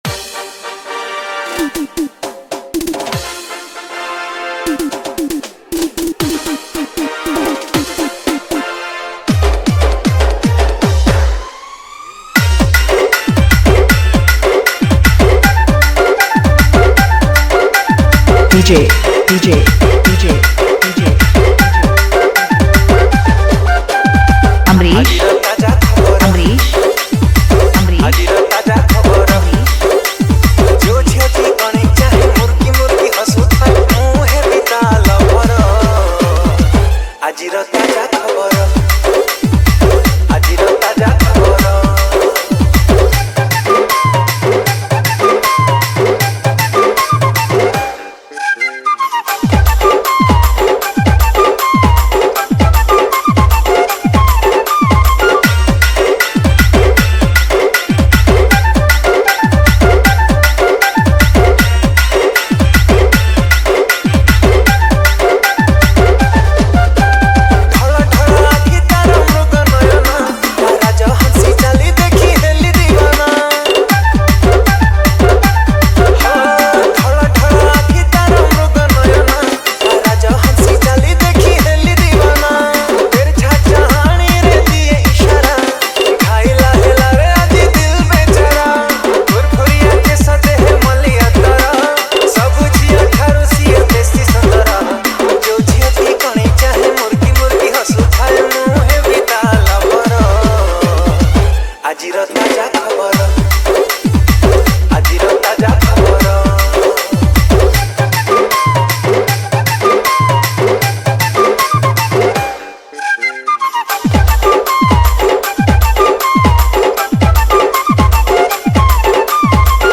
Category:  New Odia Dj Song 2024